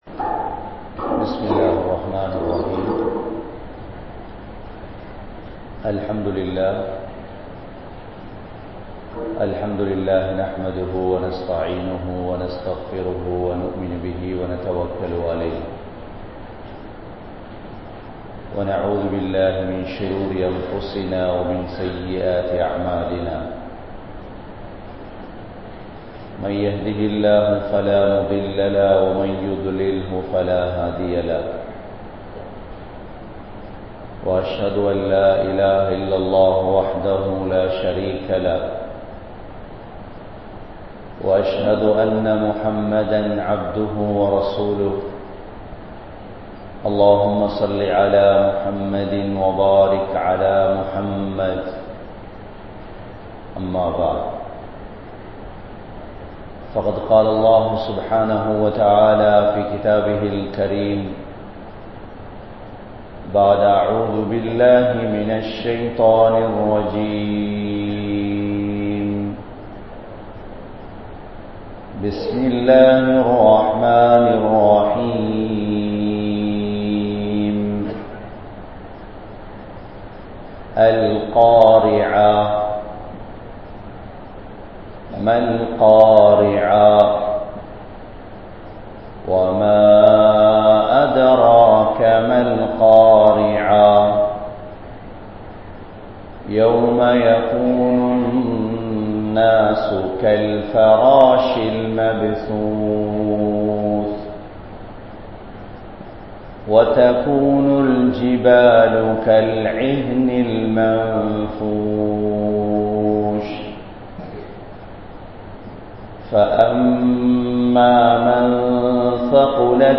Marumai Naalil Manithanin Nilamai(The situation of man in the Hereafter) | Audio Bayans | All Ceylon Muslim Youth Community | Addalaichenai
Oluvil 05, Ansari Jumua Masjith